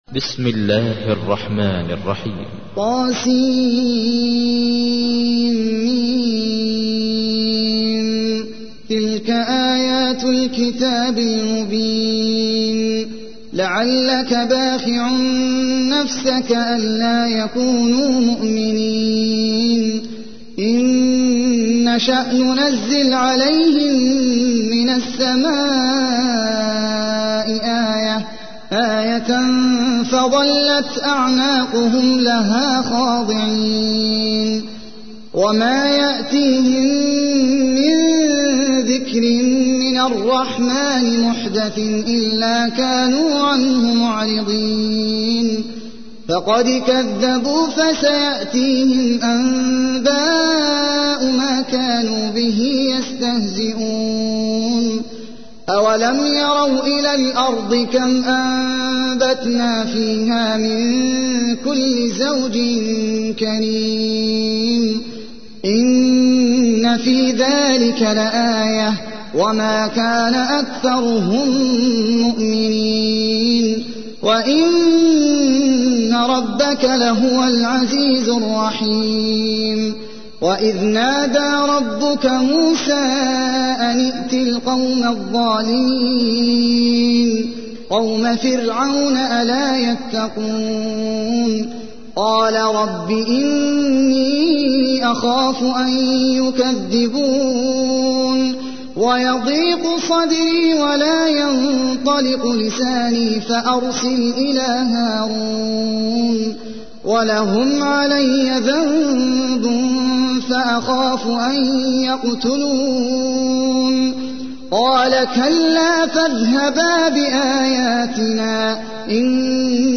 تحميل : 26. سورة الشعراء / القارئ احمد العجمي / القرآن الكريم / موقع يا حسين